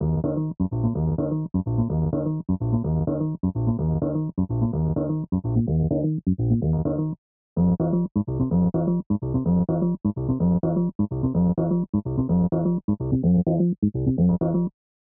basse build up 2.wav